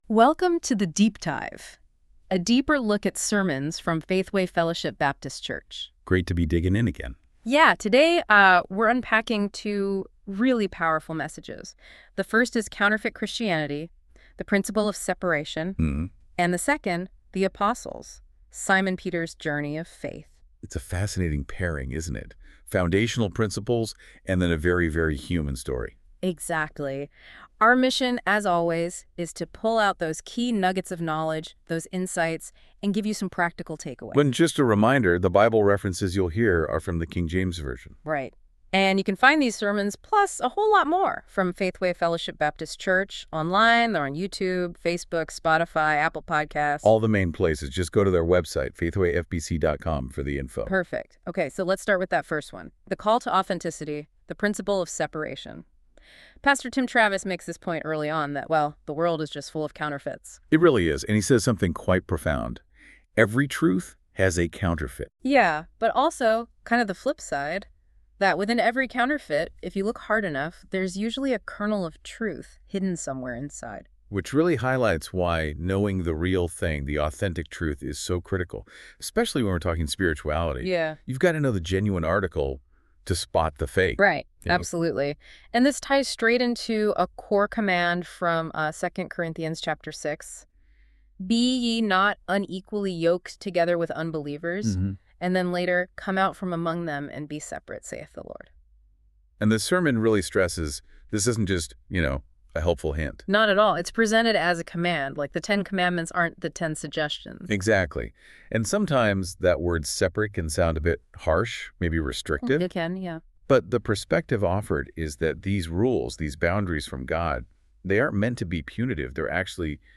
This content is AI generated for fun.